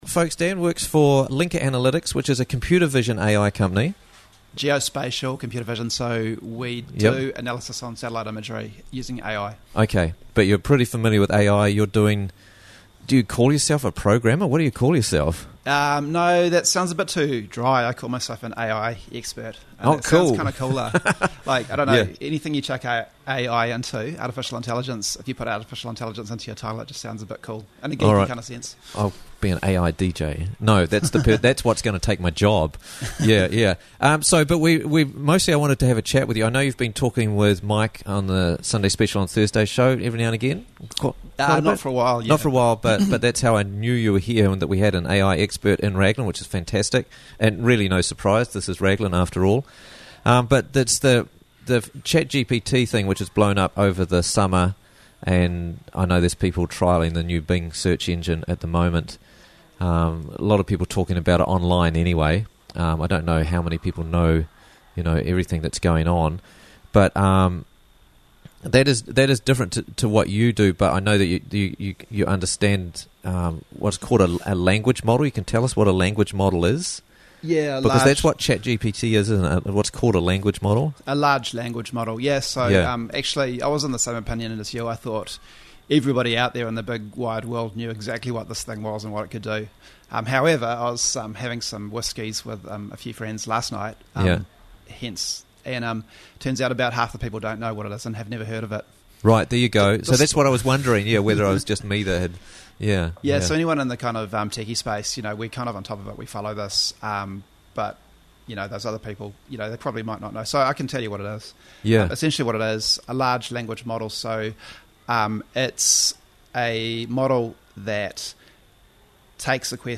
ChatGPT & Artificial Intelligence - Interviews from the Raglan Morning Show